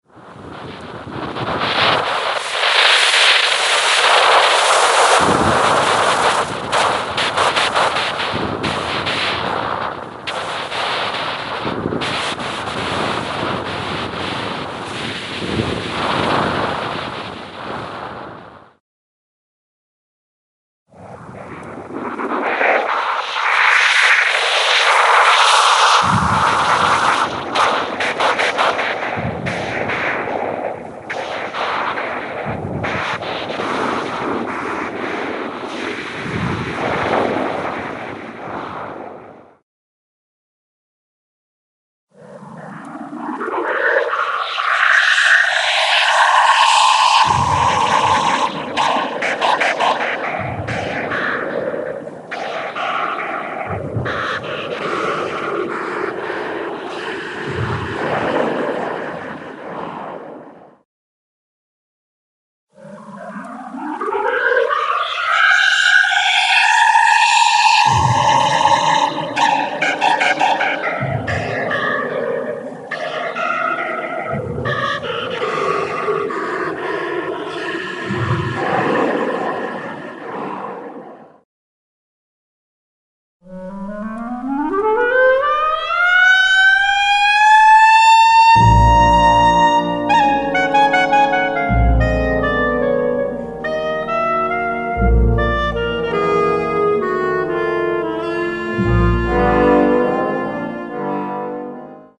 Cochlear Implant Simulation
Music1 presents a clip of a very familiar popular song, with a single male singer. First you will hear the song with 4 channels, then 8, 16, and 32 channels. Finally you will hear the original music clip.
Even at 16 and 32 channels the melody is not very good quality.